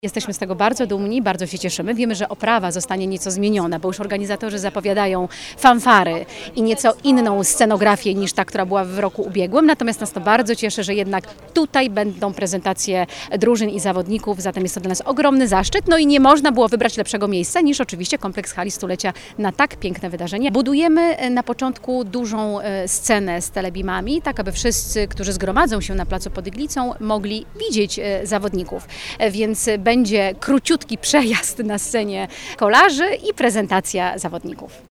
Tour de Pologne - konferencja prasowa
Na dzień przed startem, czyli w niedzielę 3 sierpnia o 18:30, przed Iglicą nastąpi prezentacja zespołów światowej klasy. Dla kibiców i fanów będą przygotowane atrakcje oraz telebimy, zaznacza Magdalena Piasecka, radna Sejmiku Województwa Dolnośląskiego, prezes Hali Stulecia we Wrocławiu.